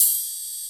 OpenHH Rnb 3.wav